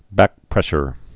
(băkprĕshər)